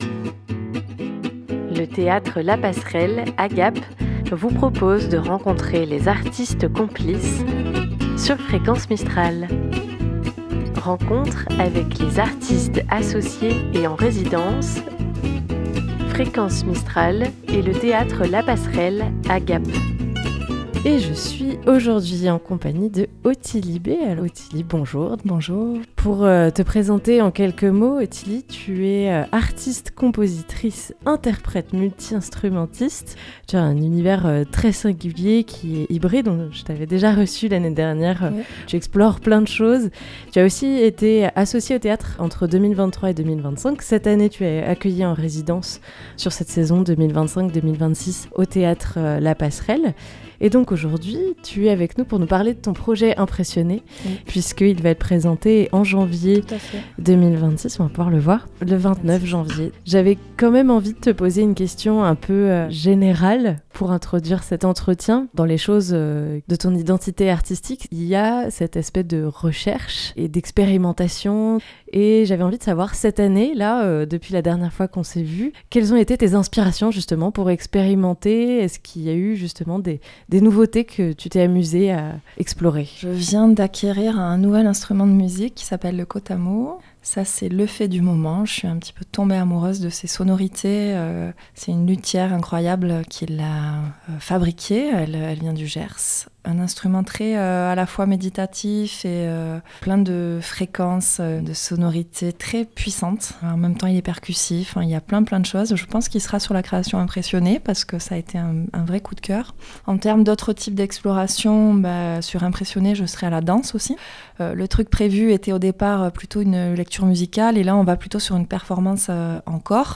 A un peu plus d'un mois de la première, l'artiste revient sur ce projet à notre micro.